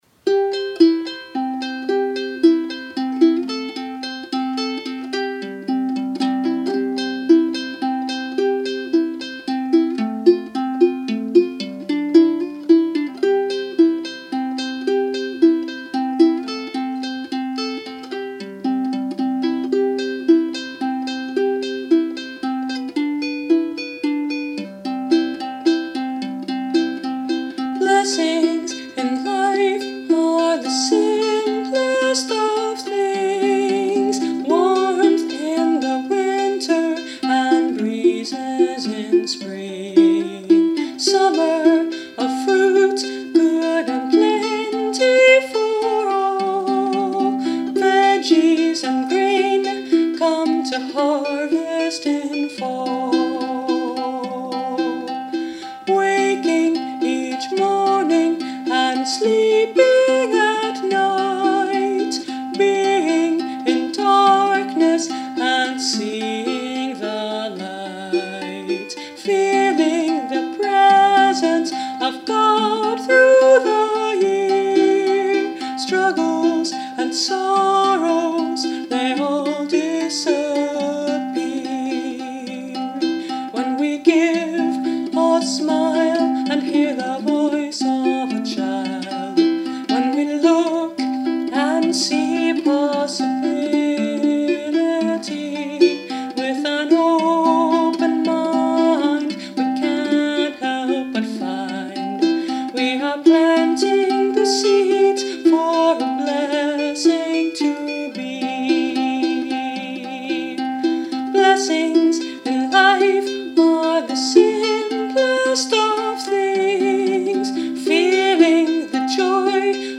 So on ukulele as written, enjoy the song, Simple Blessing.